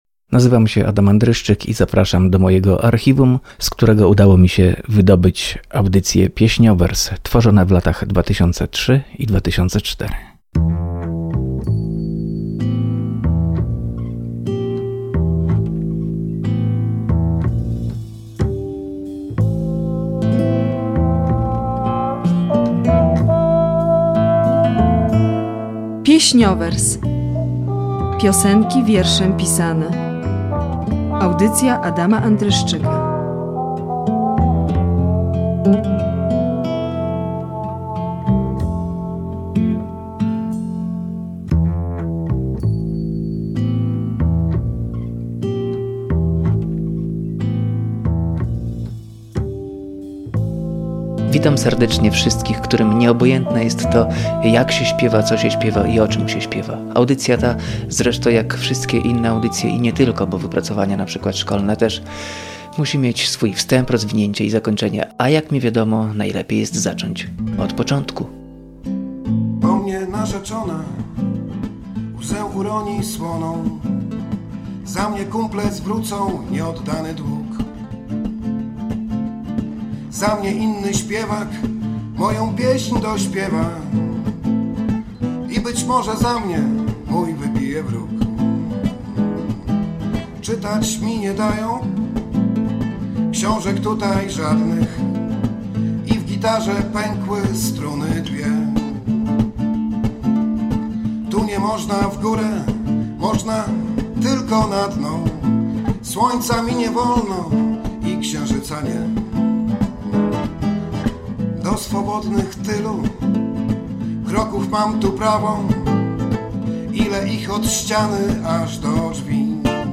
Audycja poświęcona piosence literackiej, tworzona w latach 2003-2004 dla Radia Olsztyn. W latach 2021-2022 powtarzana w Radio Danielka.